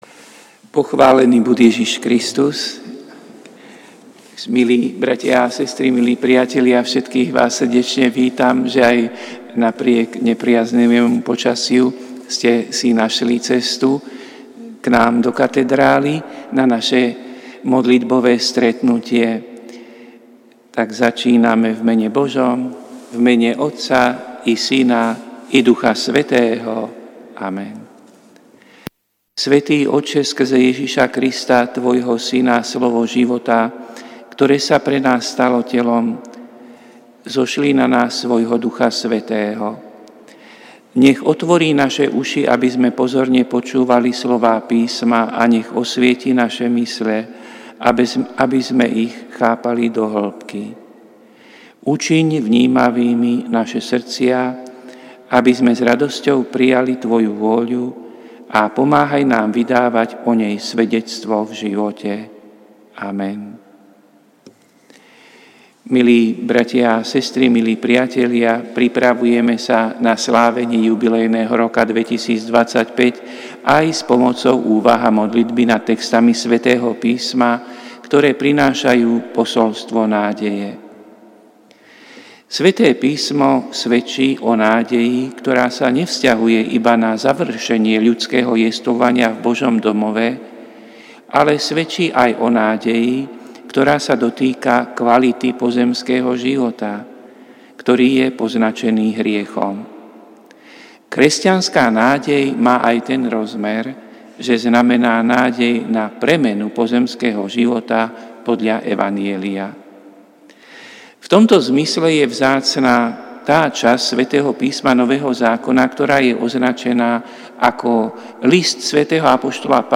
Prinášame plný text a audio záznam z Lectio divina, ktoré odznelo v Katedrále sv. Martina 2. októbra 2024.